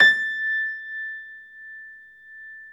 53d-pno21-A4.wav